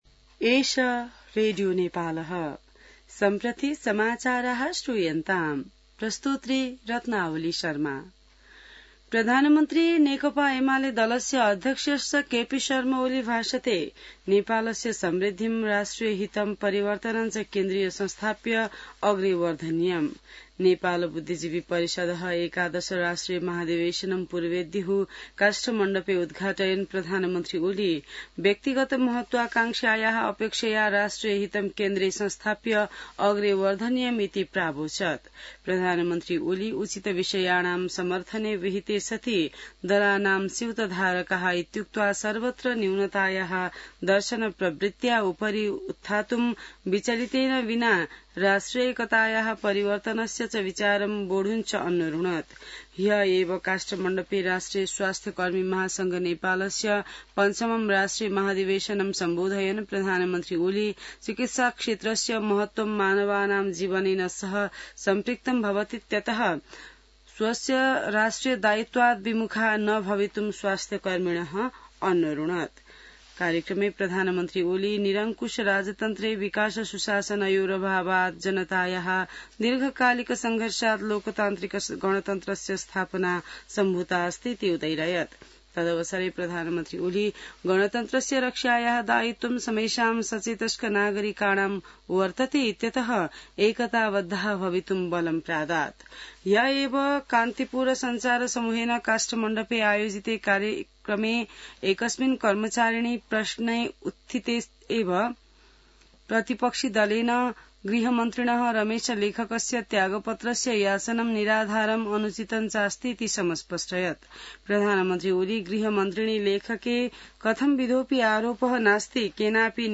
संस्कृत समाचार : १८ जेठ , २०८२